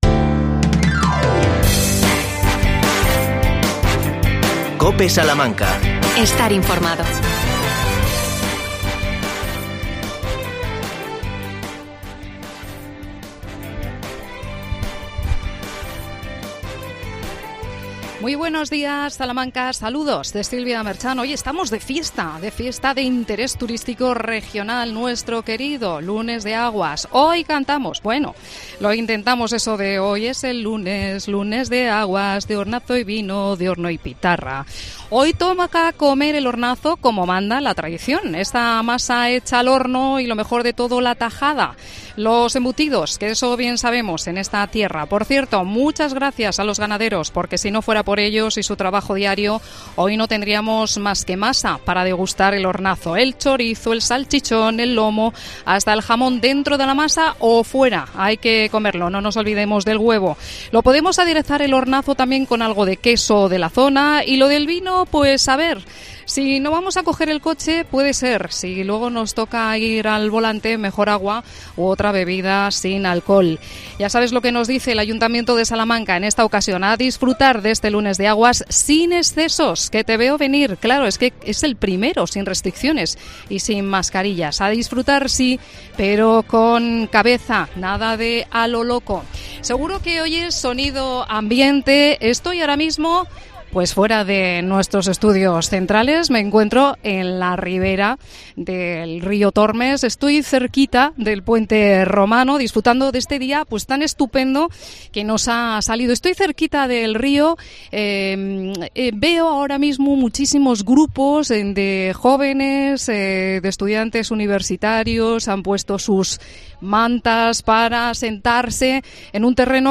AUDIO: "Lunes de Aguas" desde la orilla del Tormes.